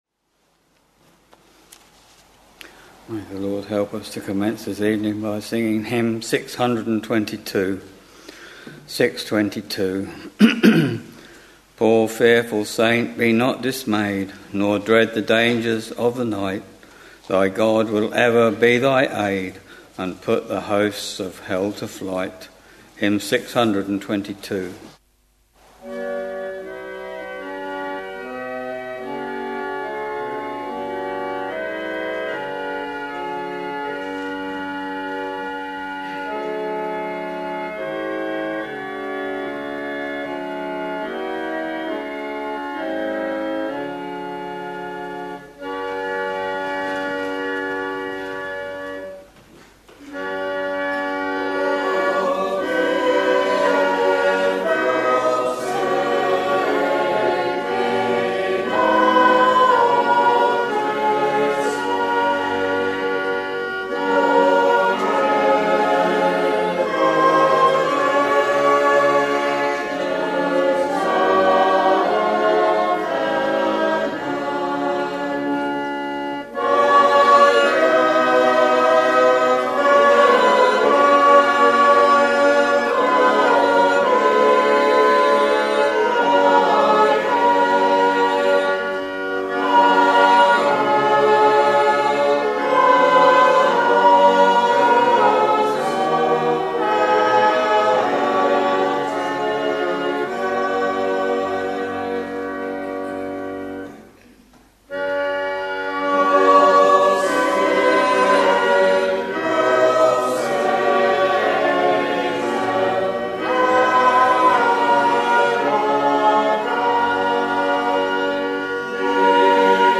Sunday, 25th August 2024 — Evening Service Preacher